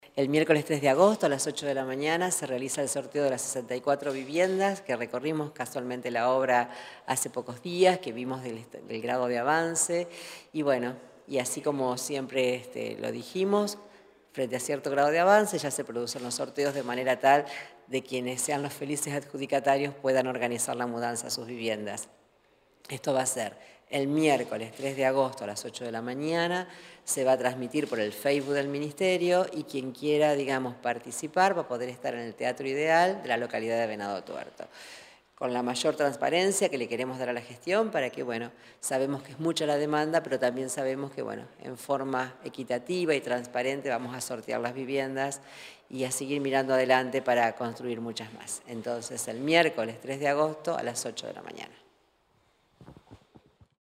Declaraciones de Frana